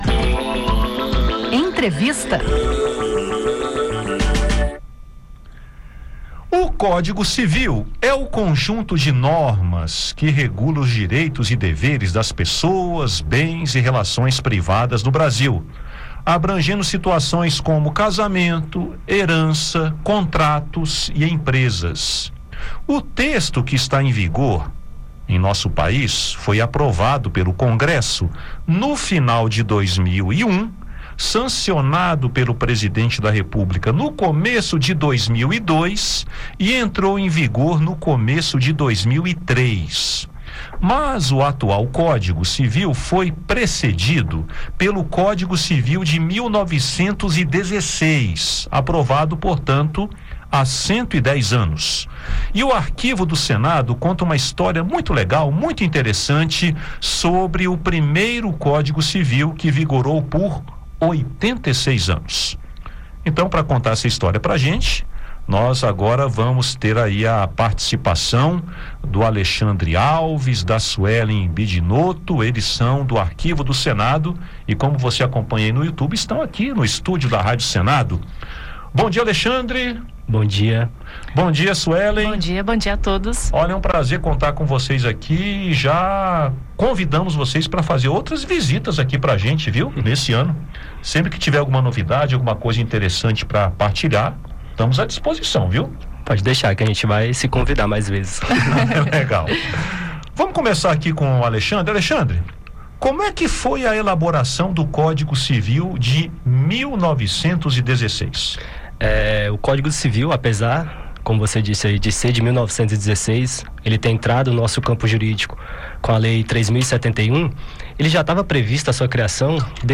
O Código Civil é um conjunto de regras que regulam os direitos e deveres no Brasil, incluindo casamento, heranças, contratos e empresas. O código atual foi aprovado em 2001 e entrou em vigor em 2003, substituindo o código de 1916. Em entrevista